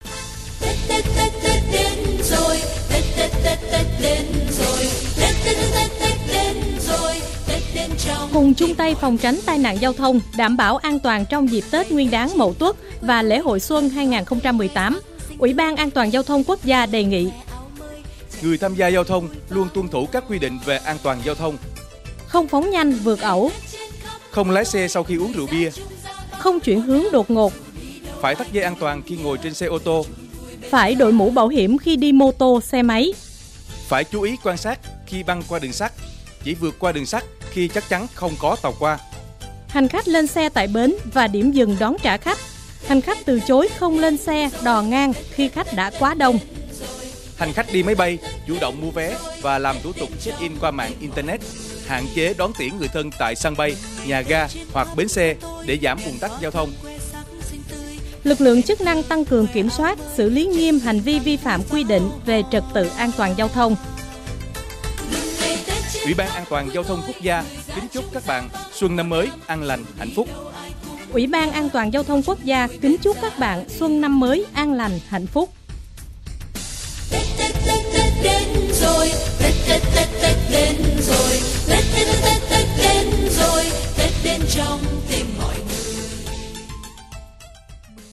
Thông điệp phát thanh Tết Nguyên đán Mậu Tuất 2018